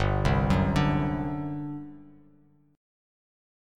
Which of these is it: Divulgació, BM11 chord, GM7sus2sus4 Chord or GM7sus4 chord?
GM7sus4 chord